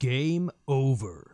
Game Over.wav